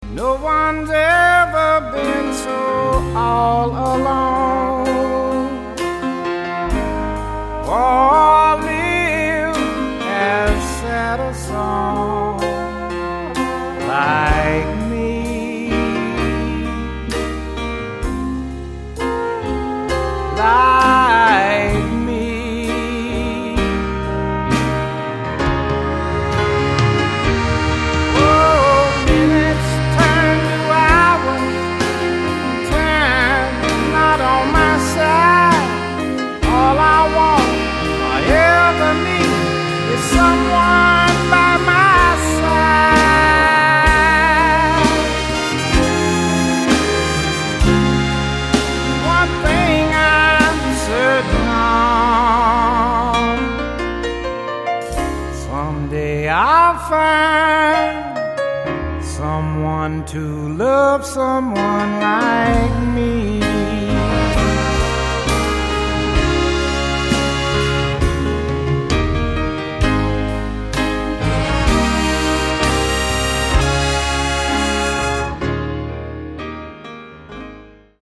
The Best in Soul, Rhythm & Blues, and Carolina Beach Music
Flute